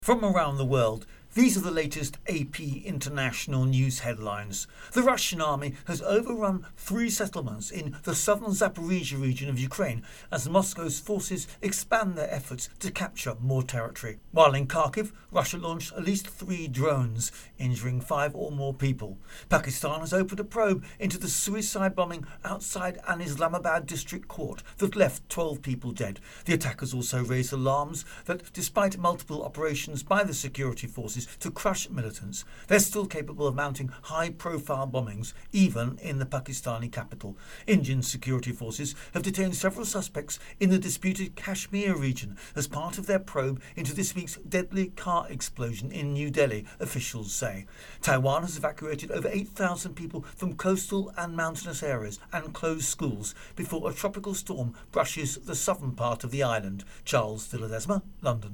The latest international news headlines